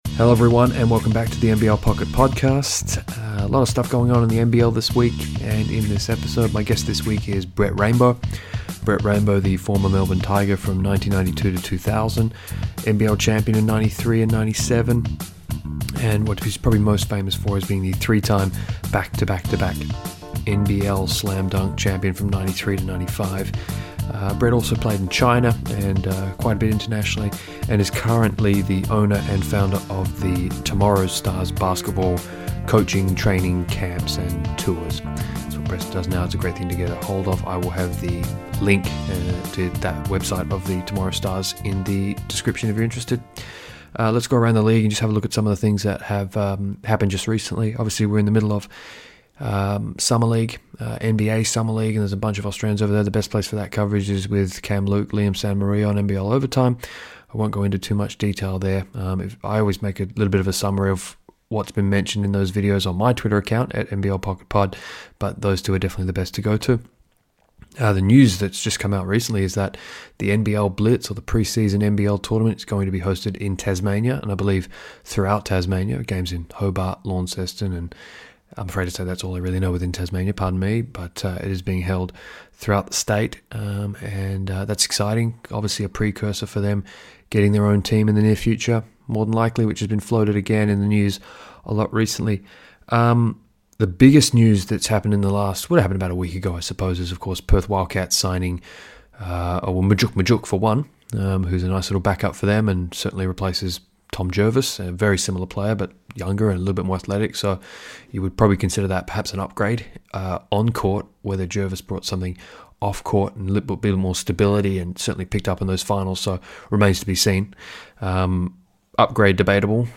I'm not terribly please with the audio in this episode. Still working out the kinks with phone call conversations.